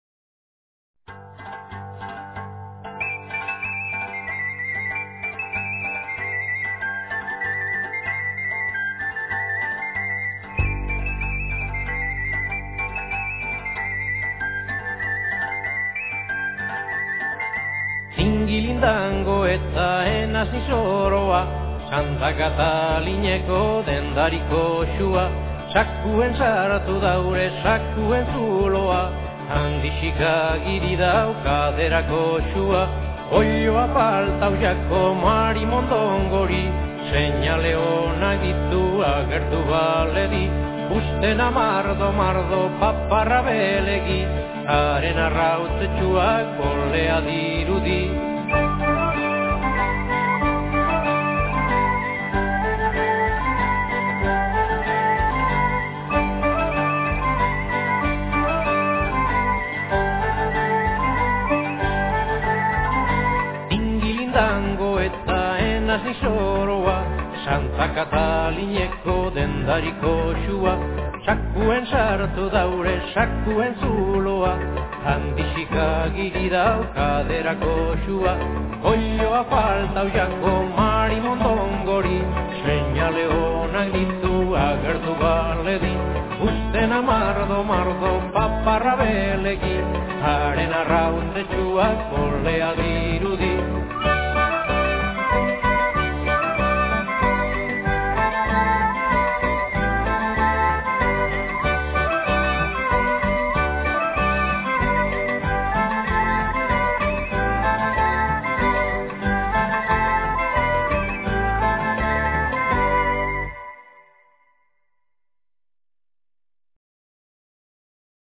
y que es un compendio de canciones infantiles de Bizkaia